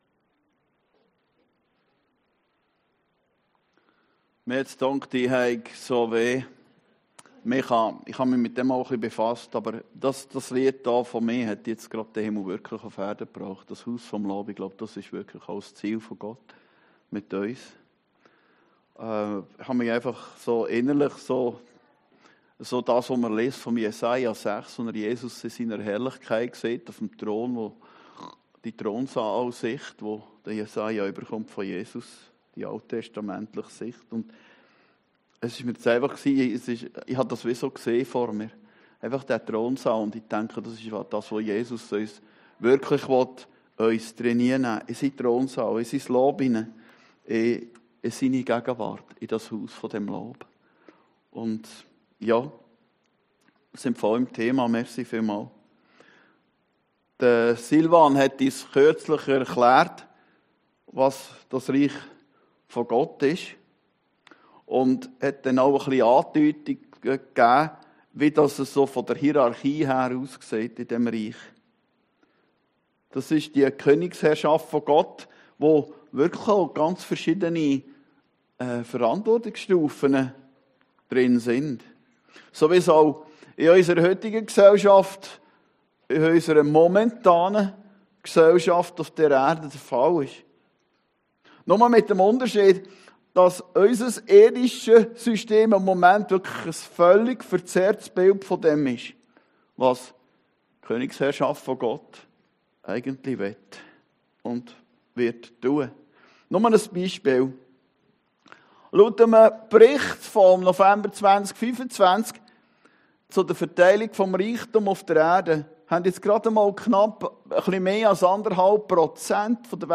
Predigten des Heilsarmee Korps Aargau Süd (Reinach AG)